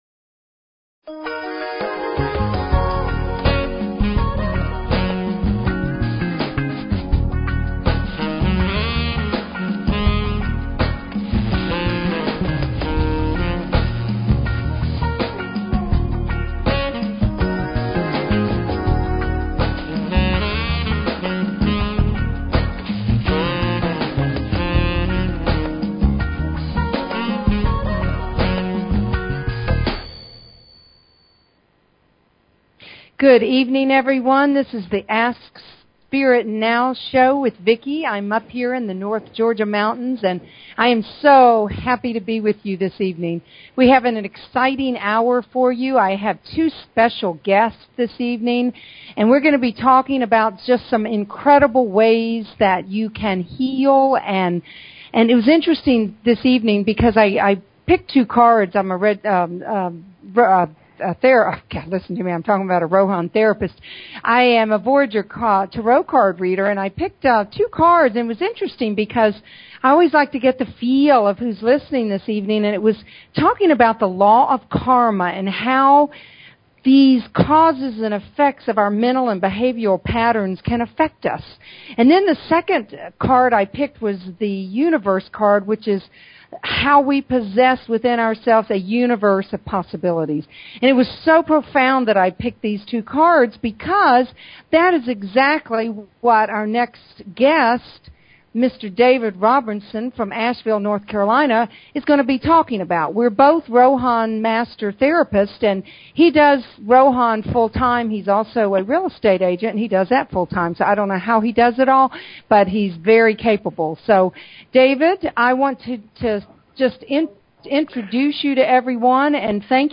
Talk Show Episode, Audio Podcast
Talk Show